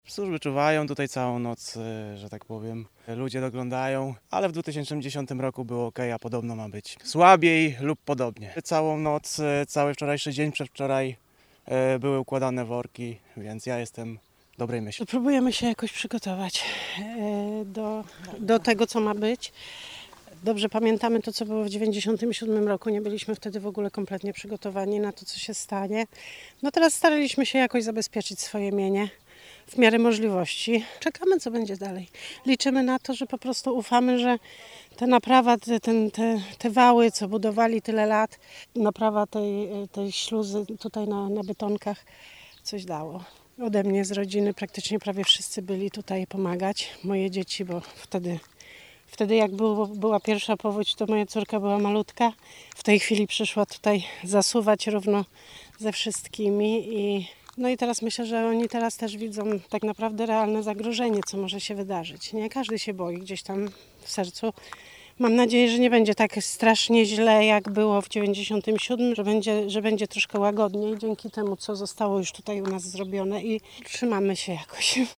Jeden z mieszkańców, pytany o swoje odczucia w obecnej sytuacji, zachowuje optymizm: Będzie wszystko okej.